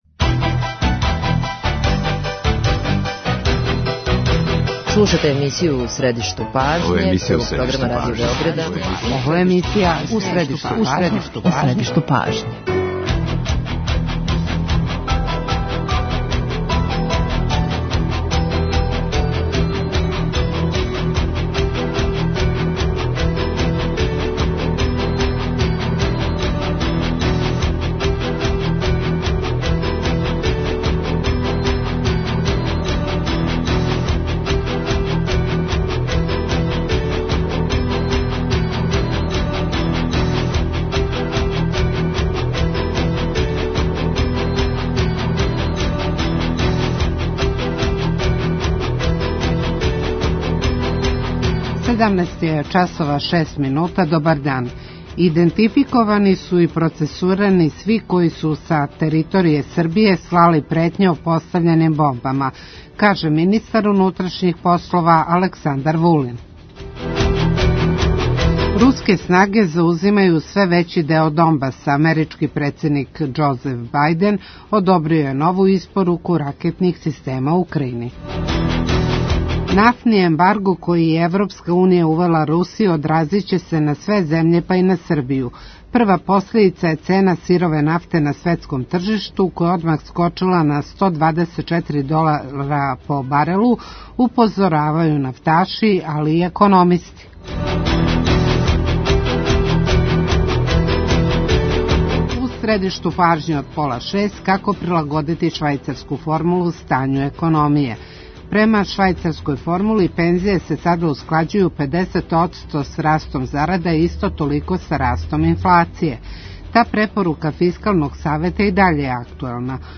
То значи да држава све мање дотира из буџета Фонду ПИО за исплату пензија. преузми : 9.85 MB У средишту пажње Autor: Редакција магазинског програма Свакога радног дана емисија "У средишту пажње" доноси интервју са нашим најбољим аналитичарима и коментаторима, политичарима и експертима, друштвеним иноваторима и другим познатим личностима, или личностима које ће убрзо постати познате.
Како формулу за индексацију пензија прилагодити кризним временима? Гост емисије је Никола Алтипармаков, члан Фискалног савета.